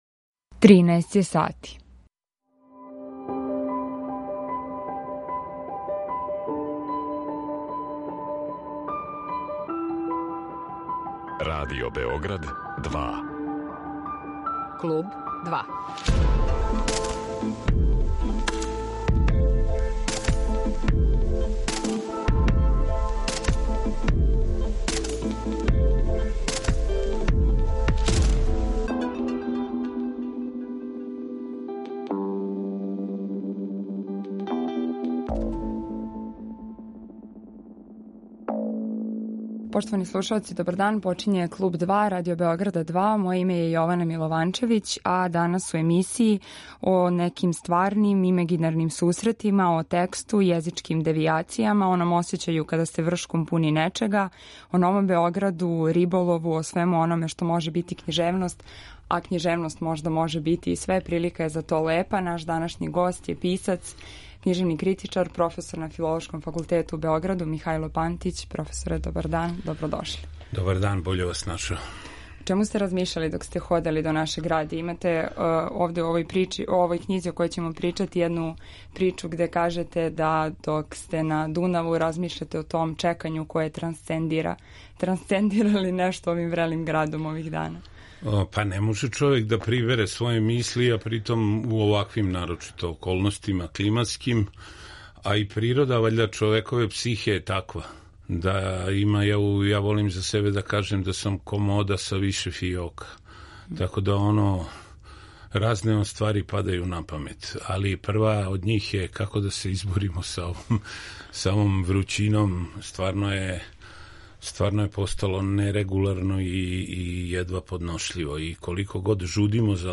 Разговор води